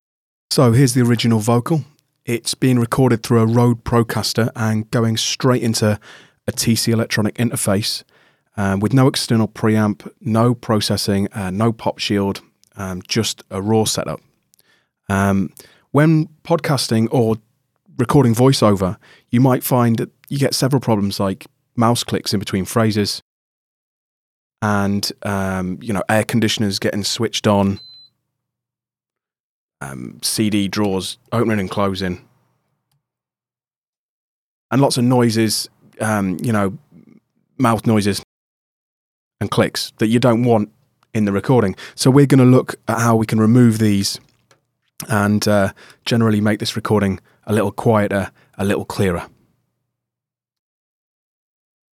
The only thing left for me to do was to add a little limiting, purely to ensure that there was no clipping or overs.
The final processed vocal.